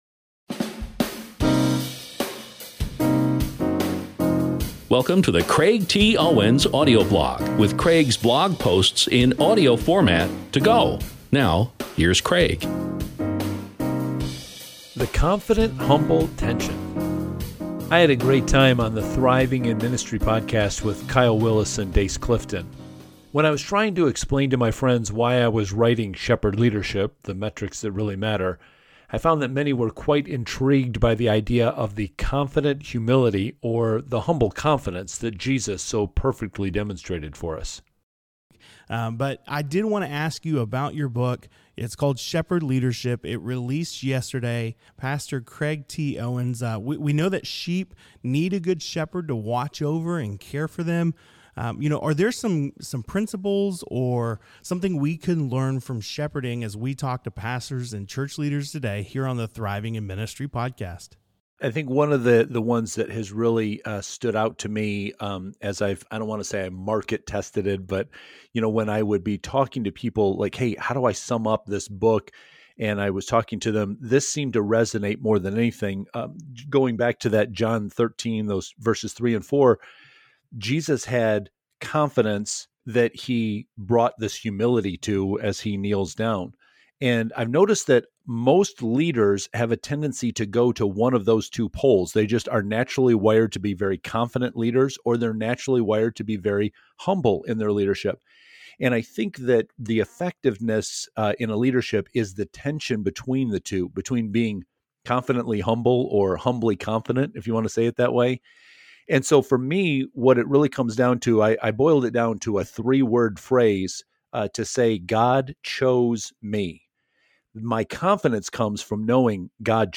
I’ll be sharing more clips from this interview soon, so please stay tuned.